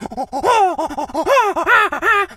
monkey_chatter_angry_13.wav